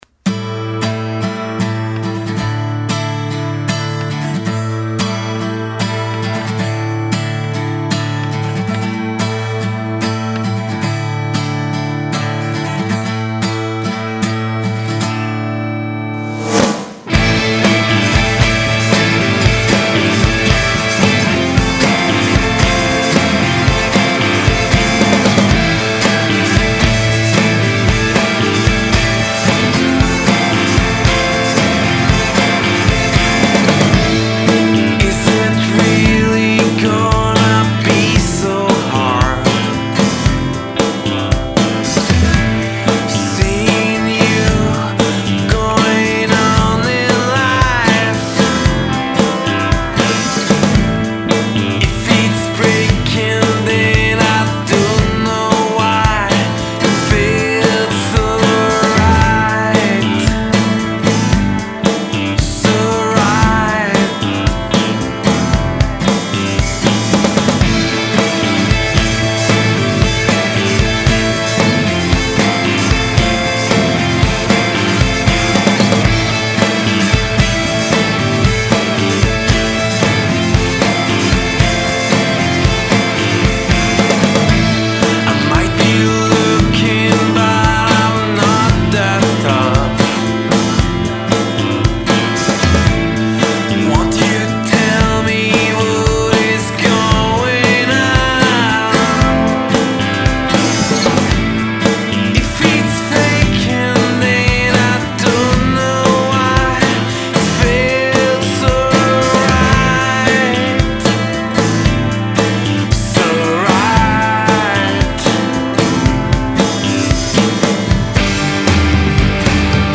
NY based band
Bass
Drums